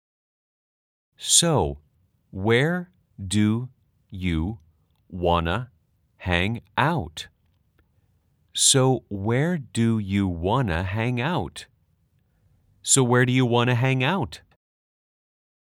문장은 3번 반복되고 속도가 점점 빨라집니다.
/ 쏘우 웨어 두유 워너 / 행아웃 /